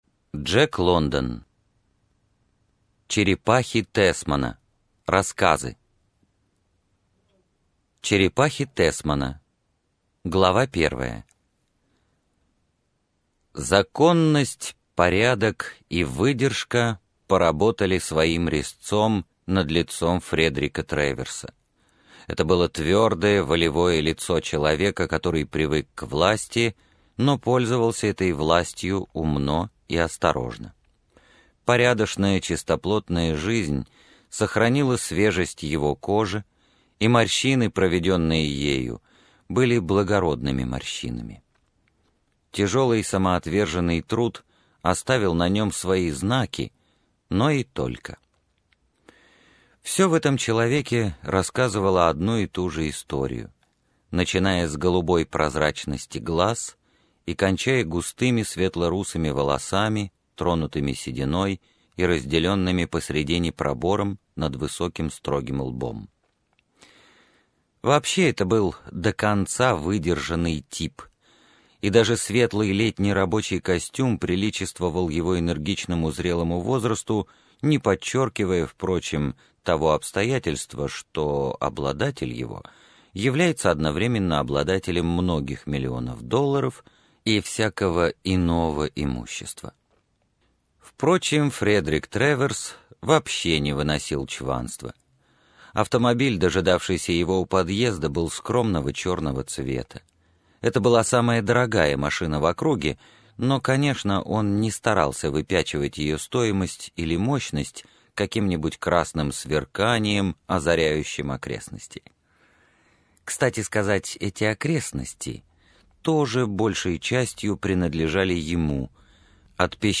Аудиокнига Черепахи Тэсмана | Библиотека аудиокниг